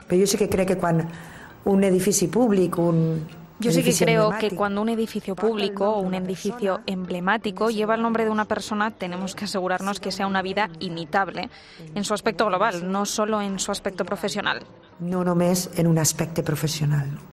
Declaraciones de Mónica Oltra